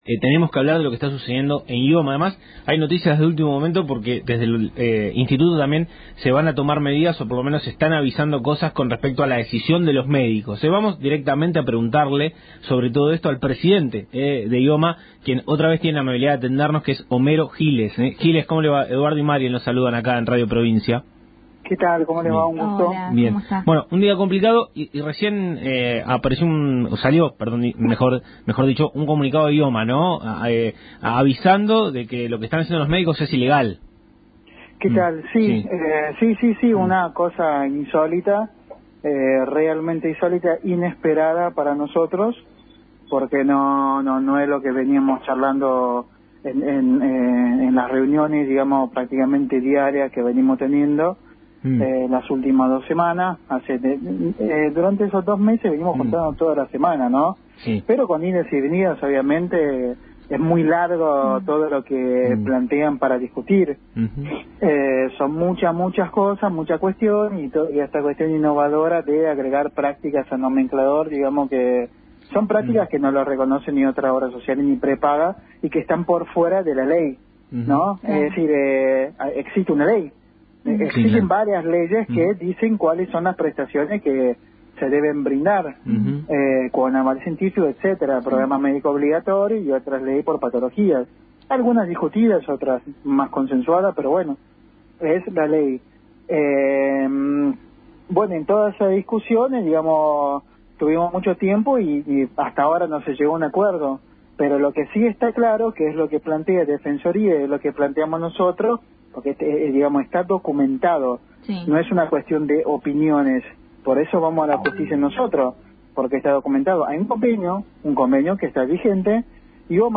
Homero Giles, presidente de IOMA, habló en Radio Provincia AM 1270, en el Programa “Tarea Fina”